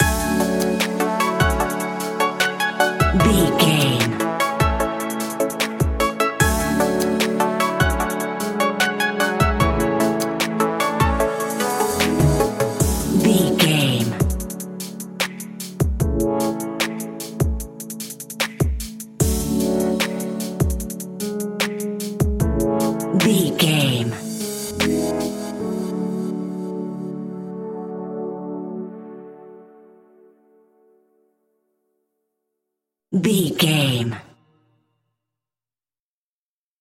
Ionian/Major
hip hop
chilled
laid back
groove
hip hop drums
hip hop synths
piano
hip hop pads